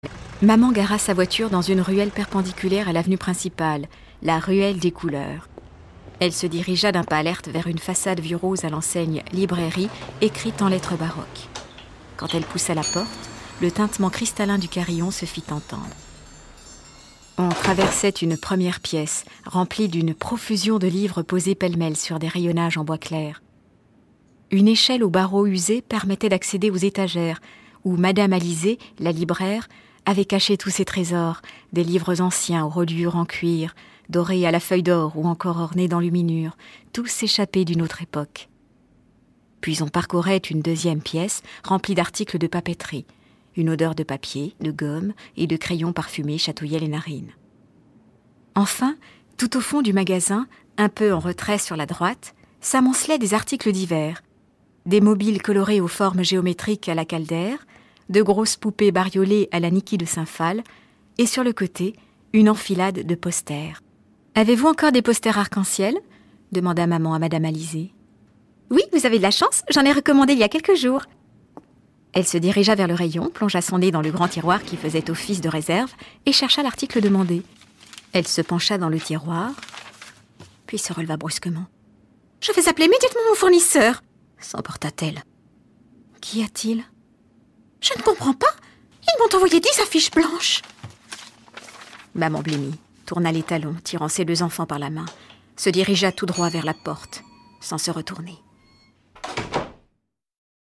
profonde et chaleureuse, qui prête avec subtilité ses multiples couleurs aux personnages extravagants...
grave et vibrante à la fois… et celles des quatre enfants du récit interprétés par quatre jeunes talents, qui complètent cette belle palette sonore de leur spontanéité rafraîchissante…
habilement mis en musique et en sons